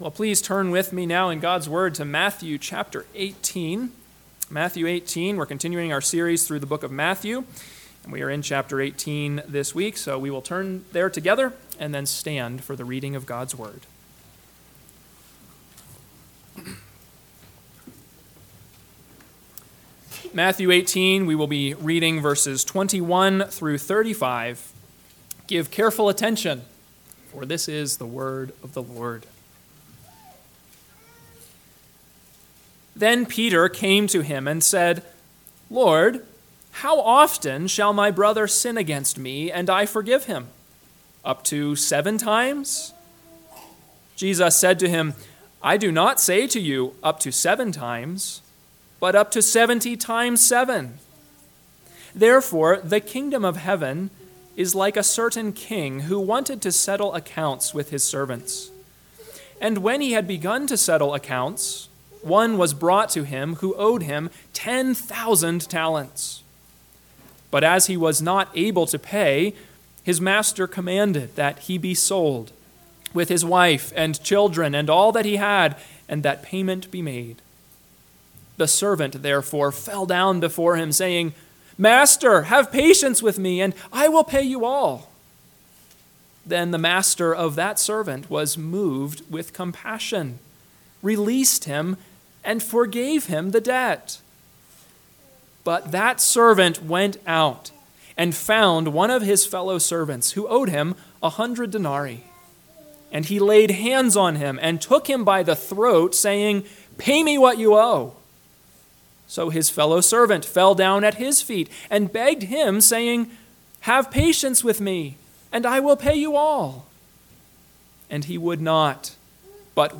AM Sermon – 5/19/2024 – Matthew 18:21-35 – Northwoods Sermons